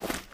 STEPS Dirt, Run 10.wav